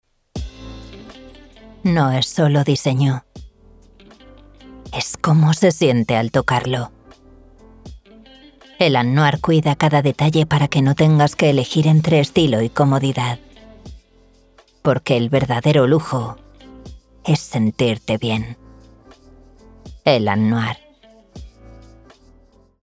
Grabaciones de Alta Calidad con Estudio Propio
Mi voz se encuentra en el rango de mujer adulta/joven y se adapta a diferentes estilos: cálida y cercana, sensual, agresiva, tímida, divertida… lo que necesites para tu proyecto.
Demo de publicidad voz grave
Cabina insonorizada